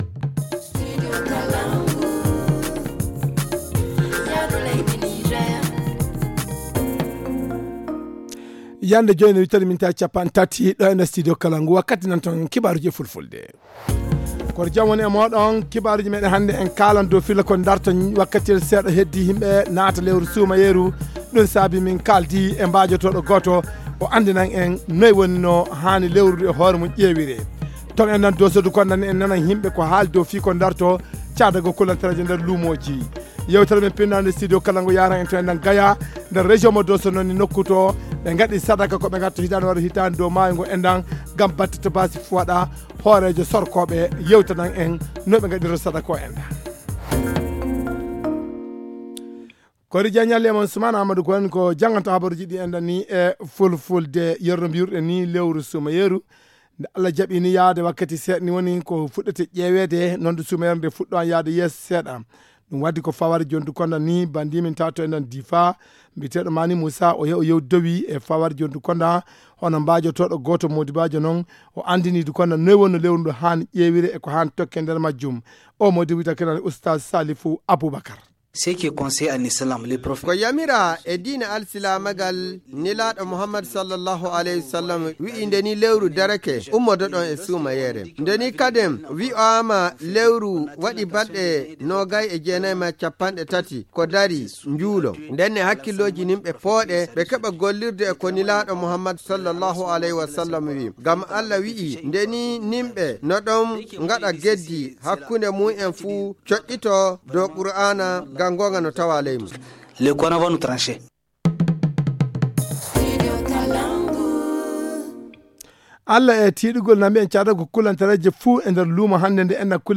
Le journal du 01 avril 2022 - Studio Kalangou - Au rythme du Niger